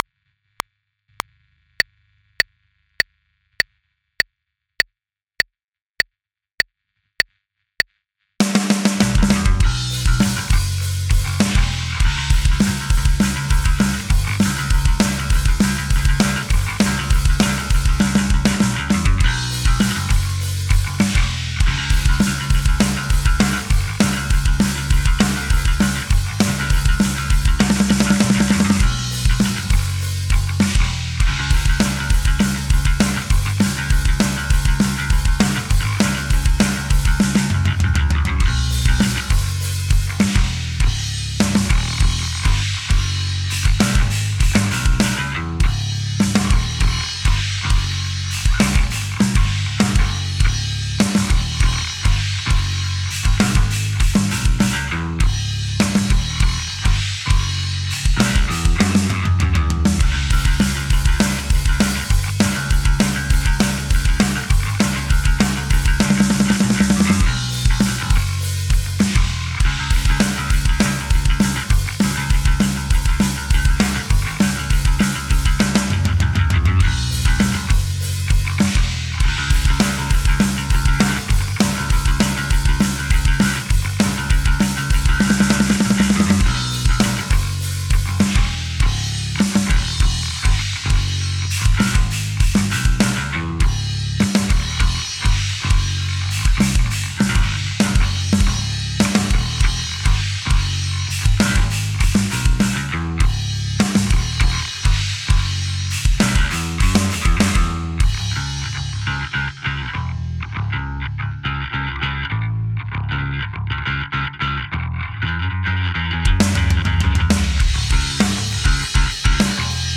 For Heavy Guitar and Solo